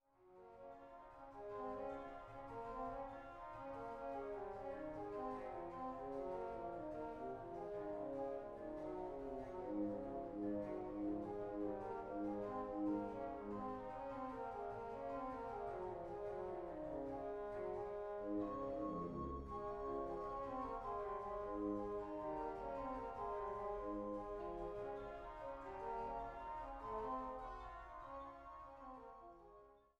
Barockoboe
Trost-Orgel in Großengottern